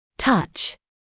[tʌʧ][тач]прикасаться, трогать; осязание; прикосновение;
touch.mp3